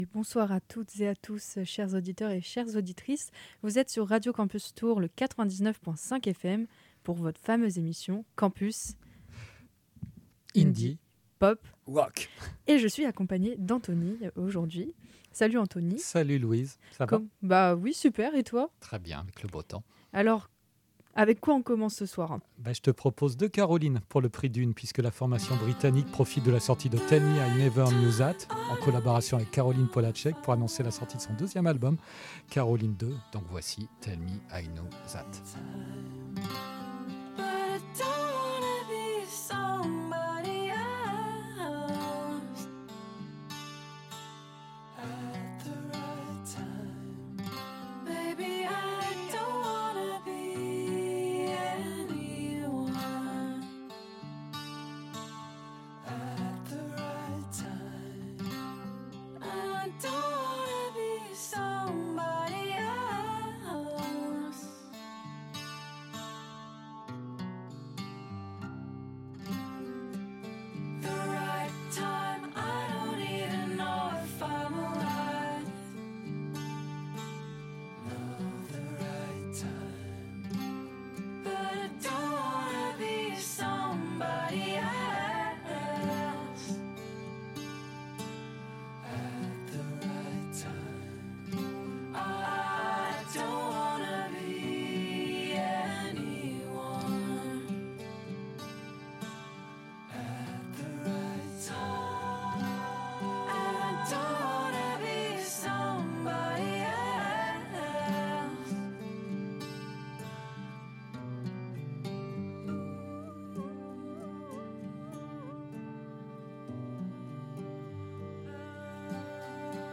Campus Indie Pop Rock, une émission de Radio Campus Tours, un lundi sur deux de 20h à 21h.